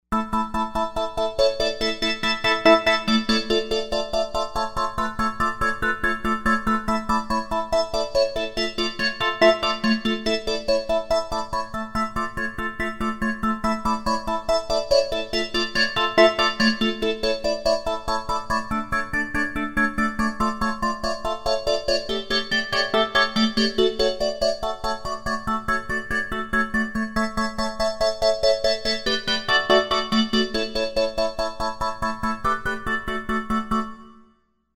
Did I get the phaser right? Is the waveform close?